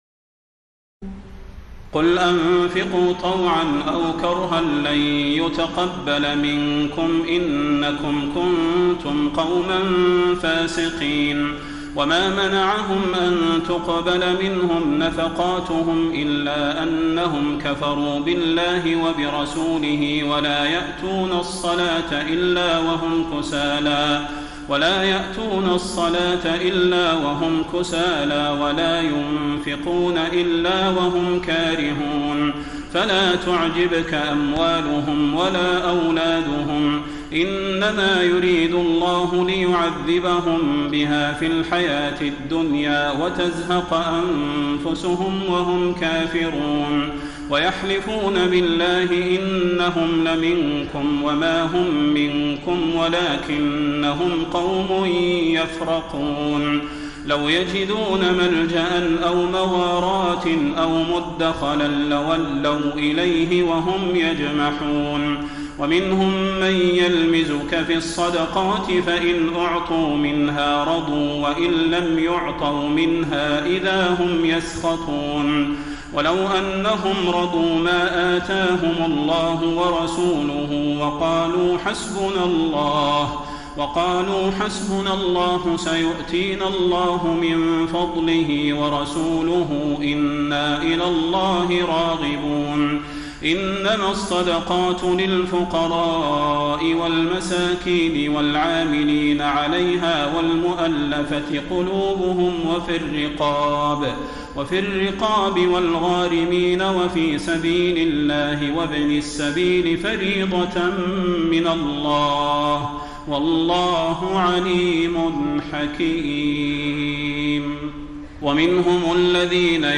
تراويح الليلة العاشرة رمضان 1433هـ من سورة التوبة (53-121) Taraweeh 10 st night Ramadan 1433H from Surah At-Tawba > تراويح الحرم النبوي عام 1433 🕌 > التراويح - تلاوات الحرمين